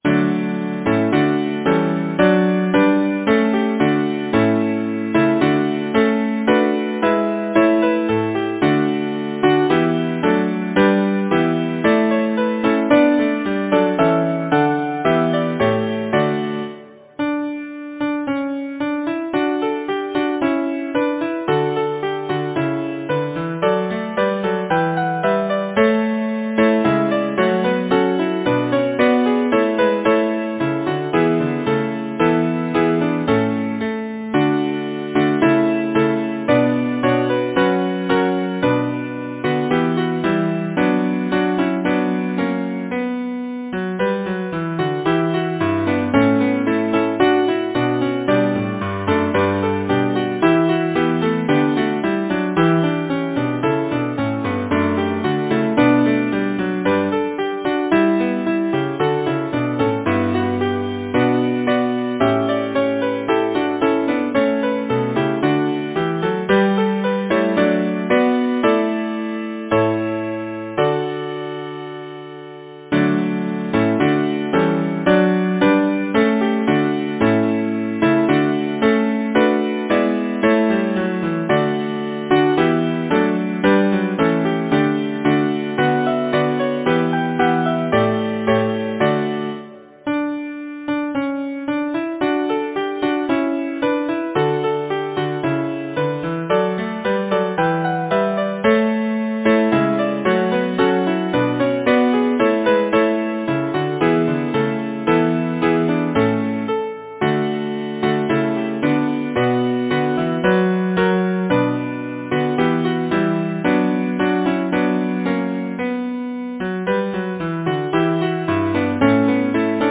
Title: May-Day Composer: James C. Beazley Lyricist: Number of voices: 4vv Voicing: SATB Genre: Secular, Partsong
Language: English Instruments: A cappella